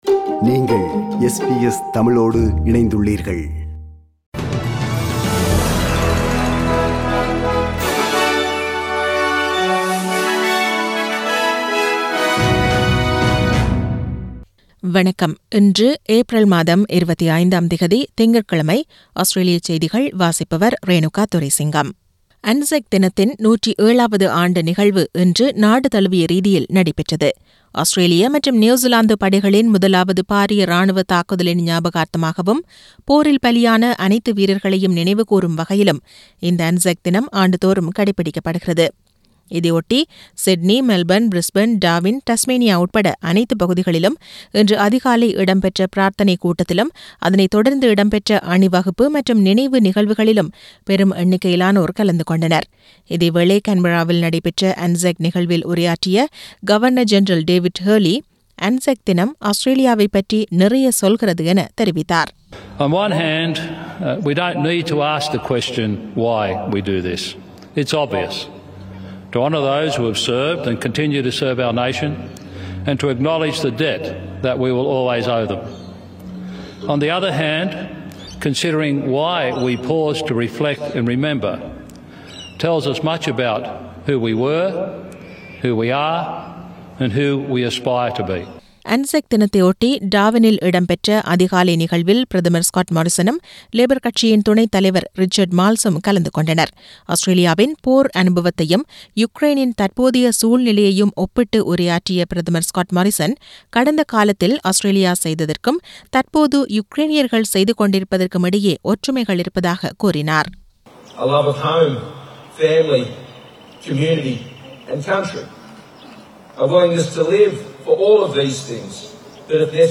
Australian news bulletin for Monday 25 Apr 2022.